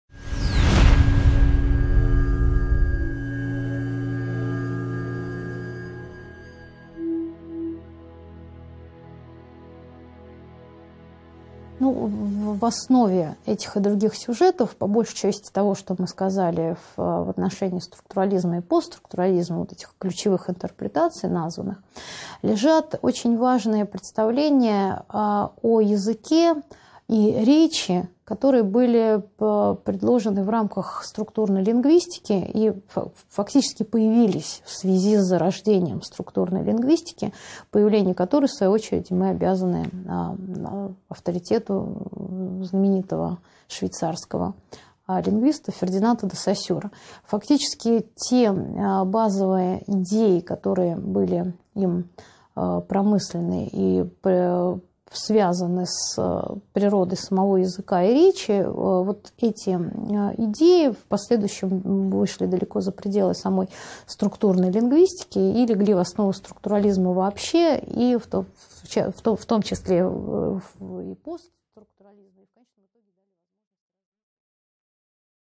Аудиокнига 15.8 Фердинанд де Соссюр: Язык есть пучок различий | Библиотека аудиокниг